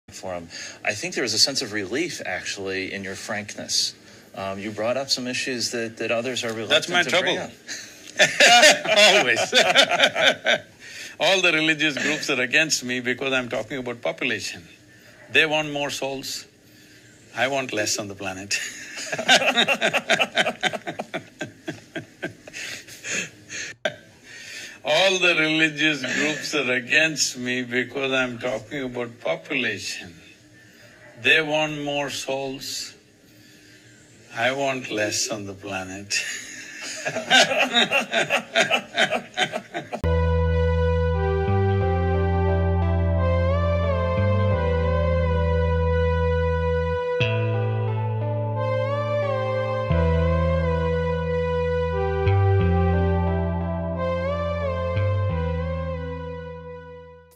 What's the difference Population control & Depopulation?Guru Leader laughs at Depopulation!